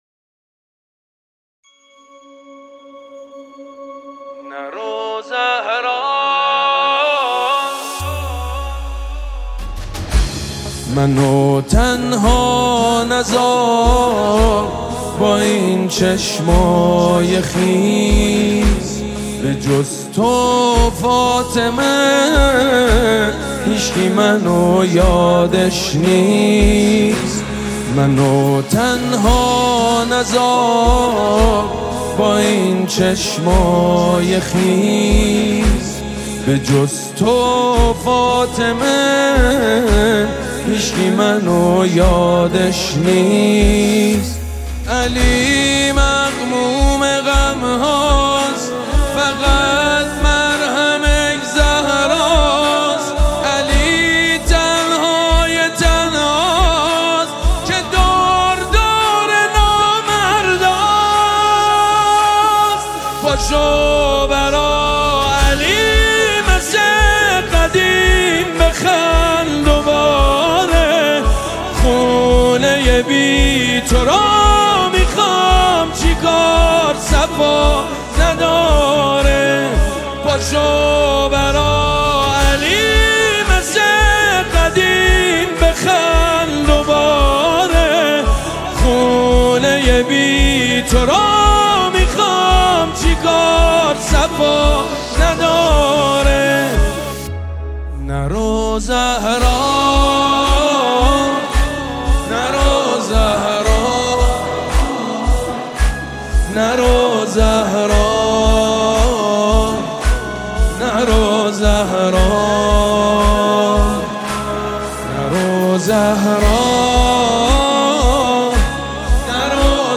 مداحی مذهبی